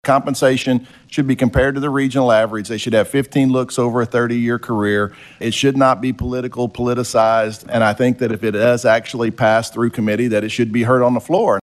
CLICK HERE to listen to commentary from Representative Neil Hays.